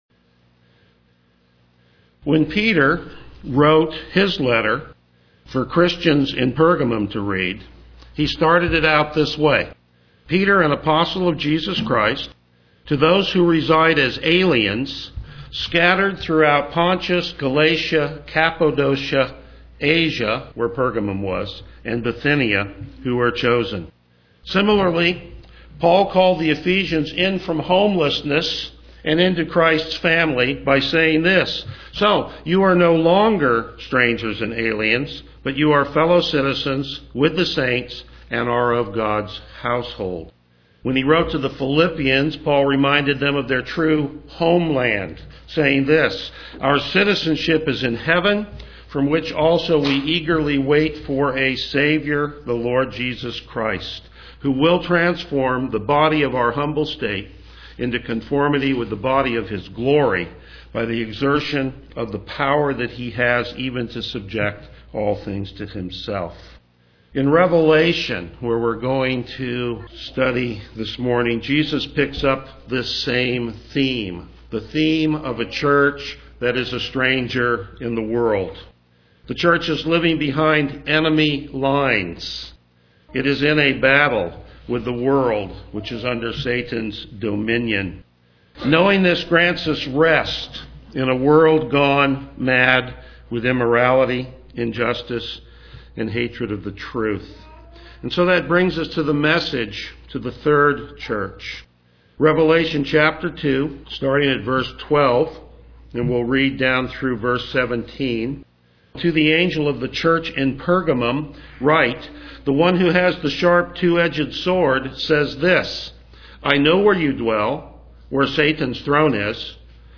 Verse By Verse Exposition